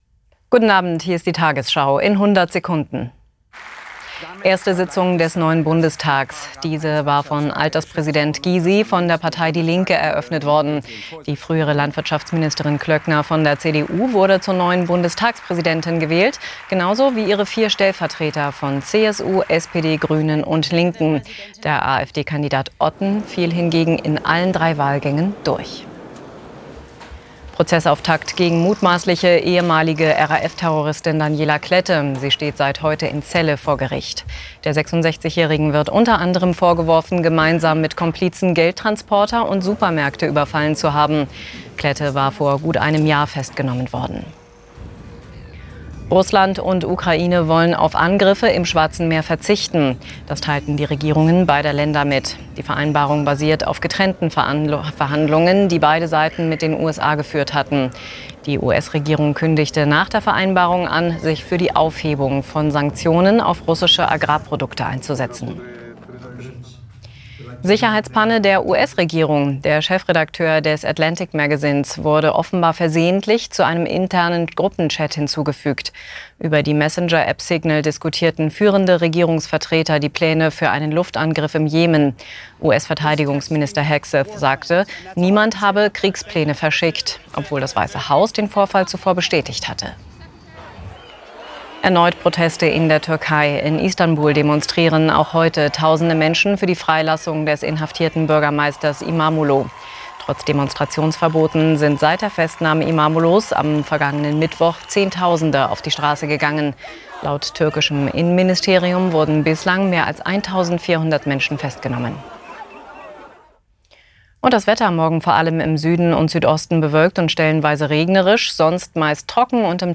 Schnell und aktuell, die Nachrichten von heute: Die tagesschau in 100 Sekunden liefert euch mehrmals am Tag ein kurzes News-Update.